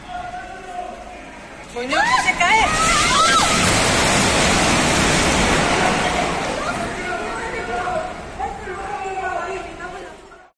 Así sonó el momento en el que se derrumba el edificio de la calle San Francisco de Teruel.